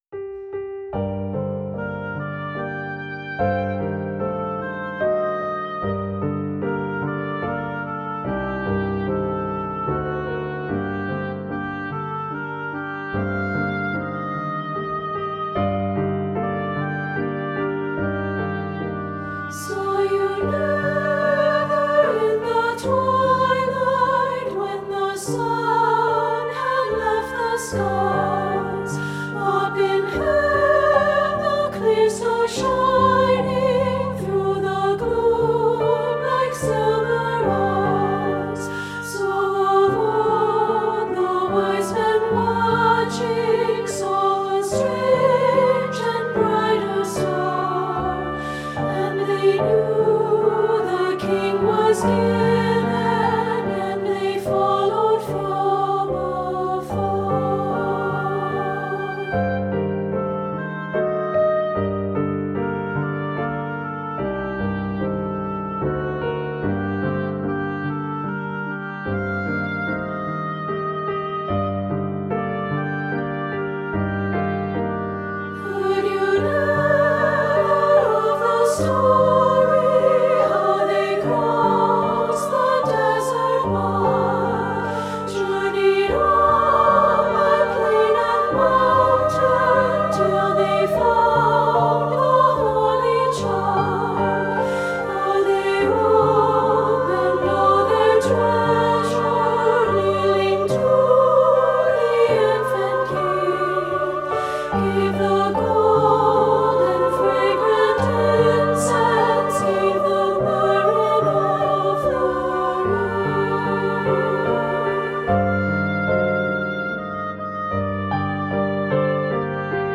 15th Century French Carol
• Voice
• Descant
• Oboe
Studio Recording
Ensemble: Treble Chorus
Key: G minor
Tempo: Truthfully (q = 74)
Accompanied: Accompanied Chorus